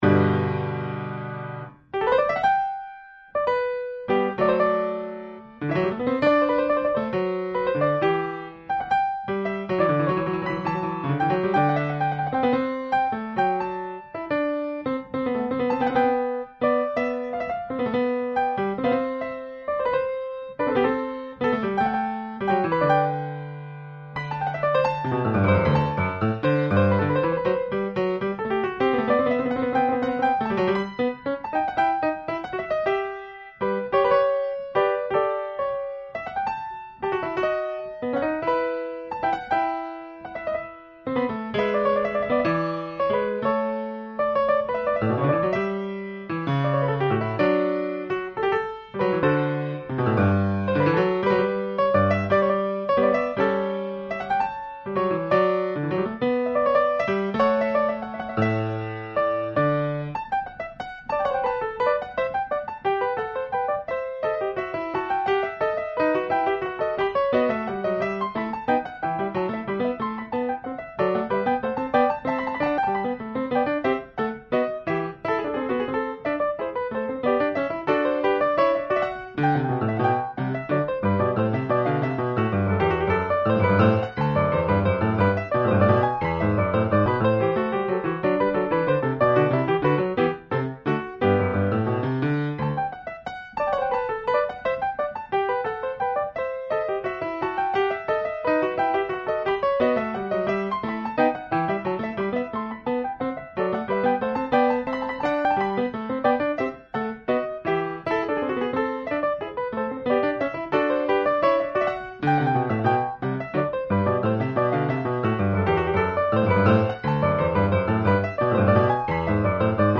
Pianoforte
Pianoforte Steinway a coda - modello S.155.